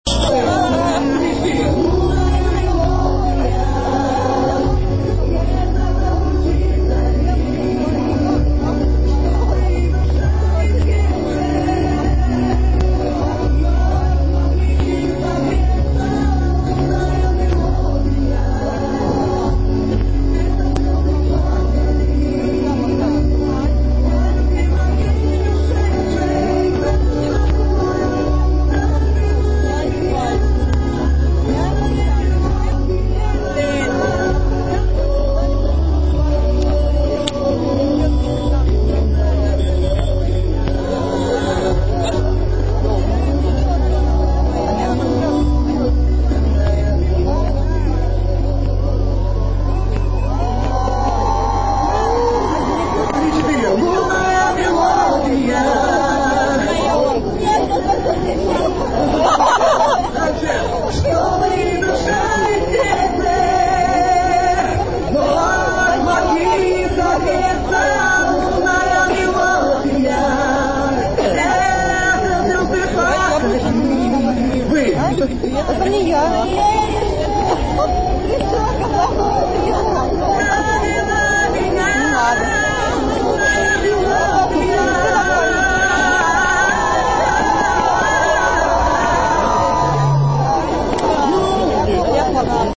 НОВОСИБИРСК, ПЛОЩАДЬ ЛЕНИНА, 4 НОЯБРЯ 2005 ГОДА